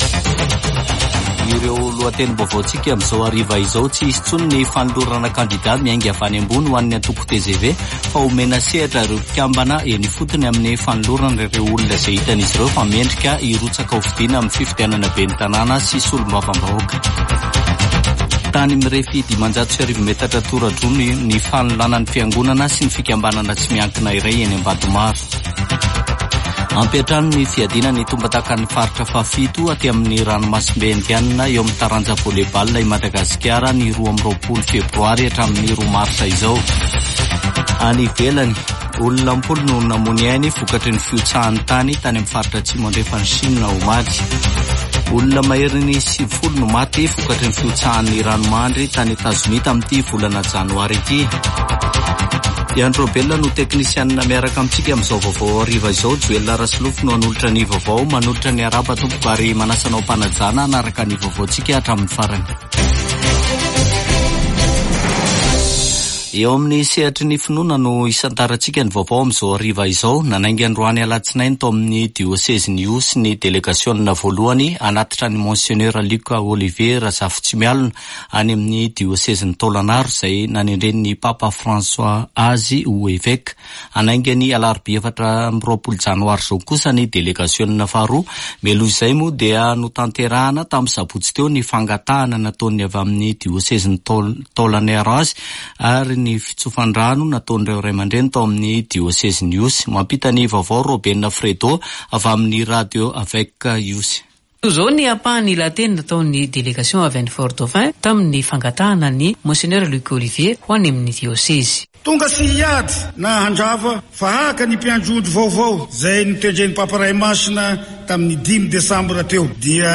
[Vaovao hariva] Alatsinainy 22 janoary 2024